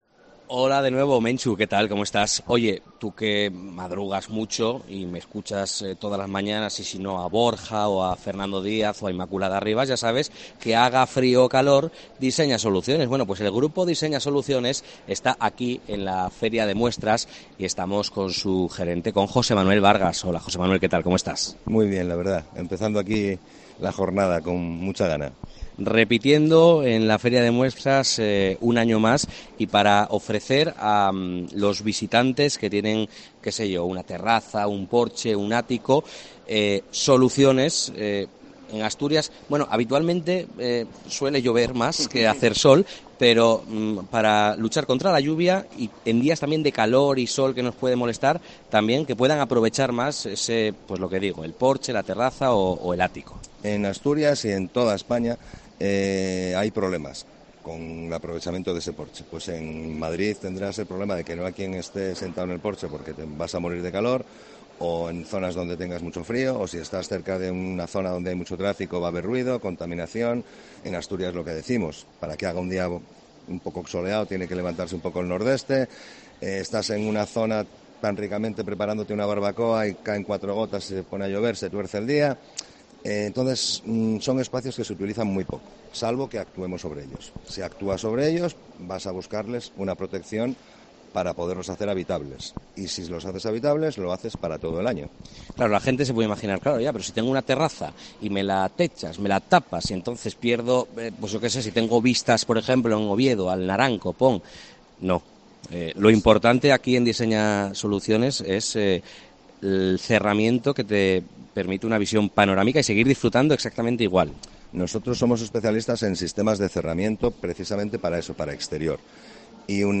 en el programa especial que COPE Asturias emite desde el Recinto Ferial Luis Adaro
entrevista